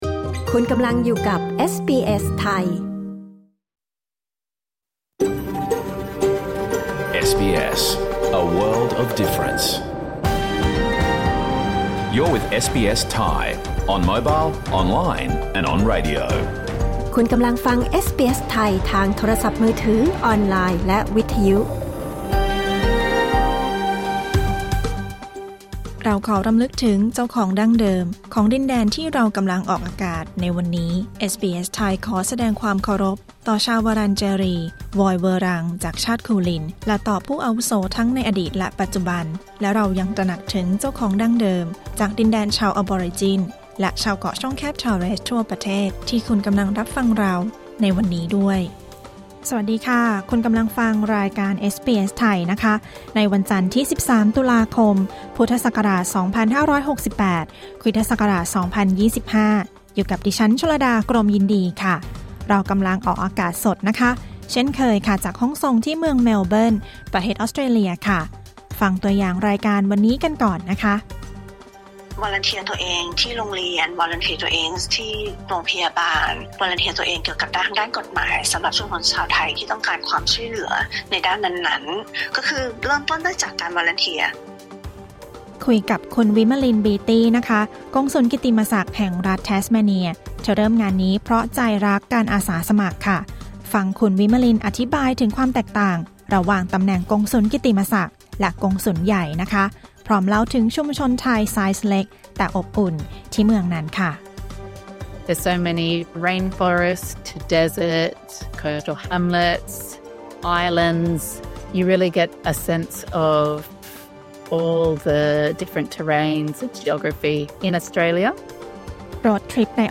รายการสด 13 ตุลาคม 2568